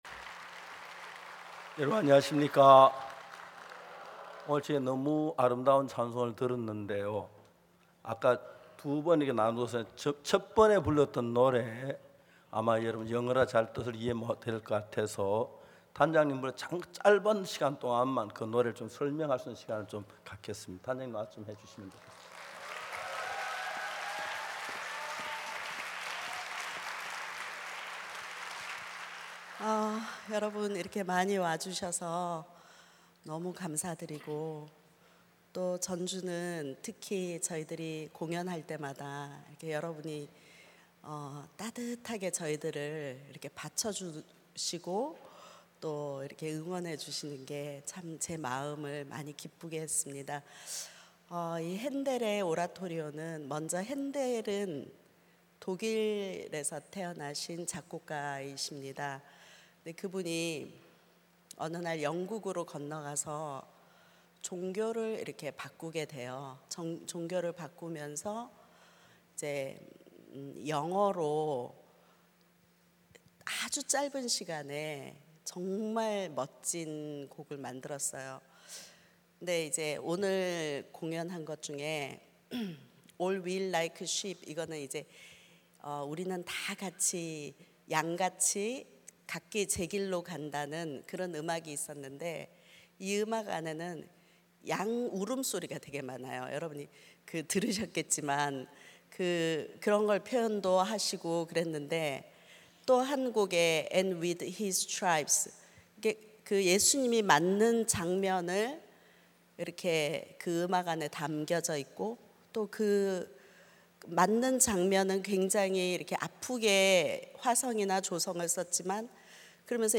성경세미나 설교를 굿뉴스티비를 통해 보실 수 있습니다.